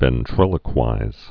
(vĕn-trĭlə-kwīz)